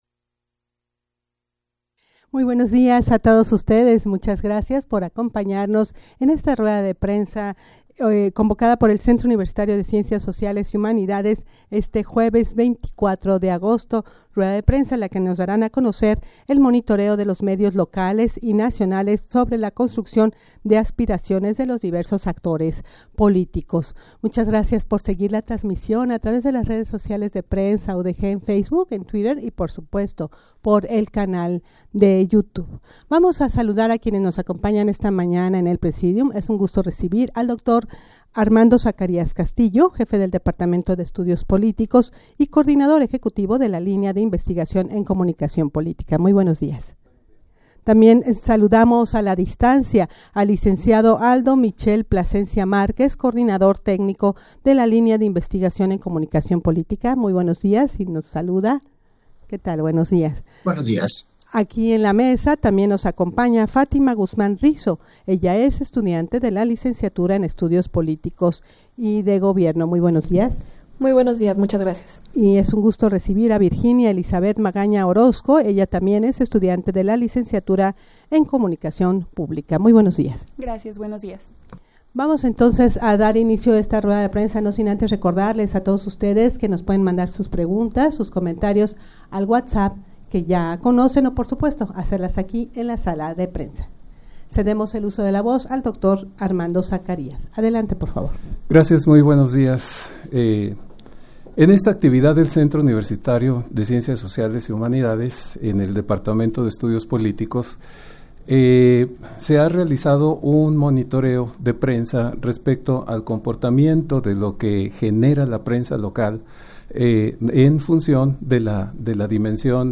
Audio de la Rueda de Prensa
rueda-de-prensa-para-dar-a-conocer-el-monitoreo-de-los-medios-locales-y-nacionales-_0.mp3